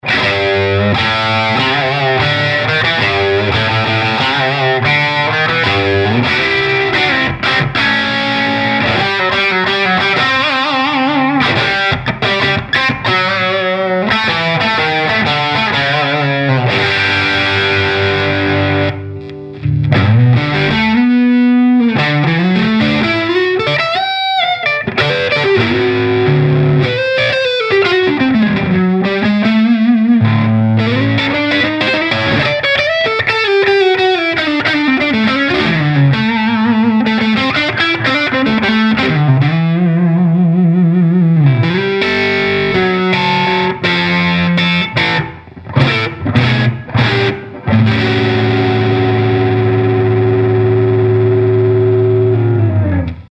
• Falante de Guitarra
• British Style
O Falante Crazy Diamond da BGT SPEAKER possui médios controlados e suaves, graves bem presentes e firmes, médios agudos cristalino e não ardido.